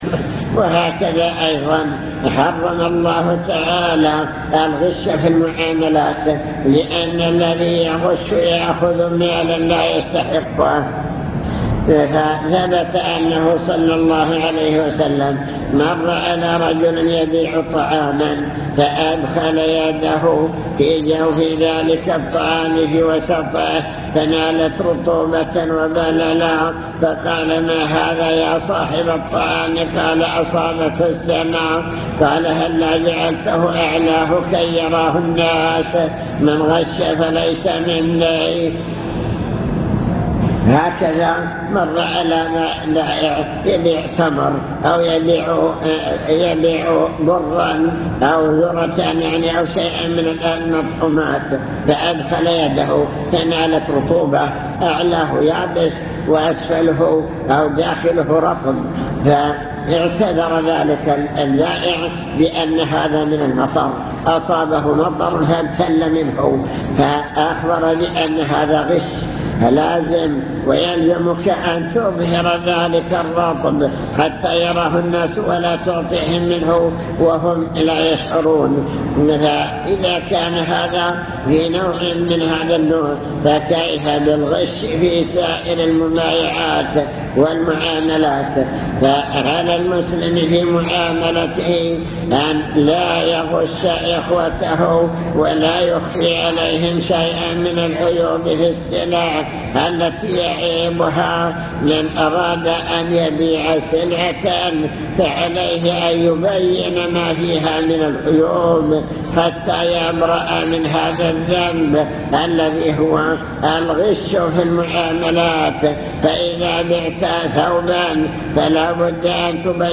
المكتبة الصوتية  تسجيلات - محاضرات ودروس  محاضرة بعنوان المكسب الحلال والمكسب الحرام